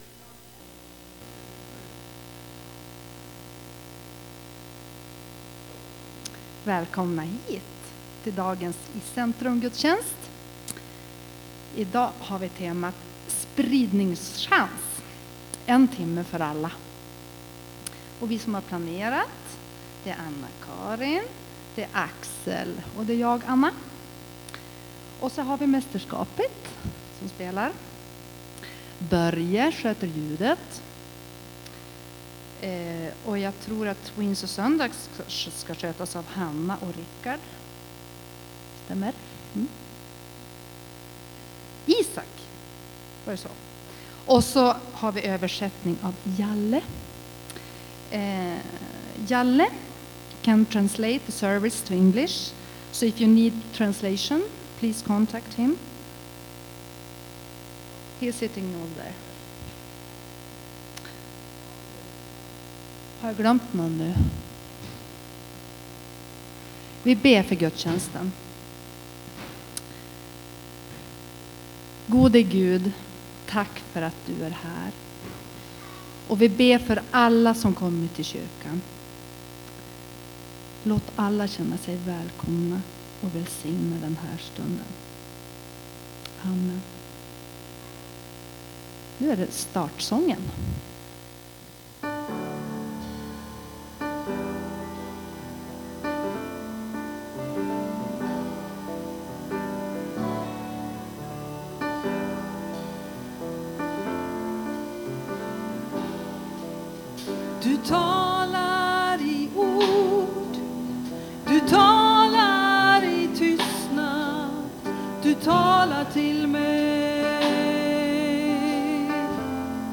Efter sommaruppehållet startade höstterminen med en iCentrum-gudstjänst. Den handlade om missionsuppdraget som vi delar med alla troende.
Lyssna gärna till Gudstjänsten!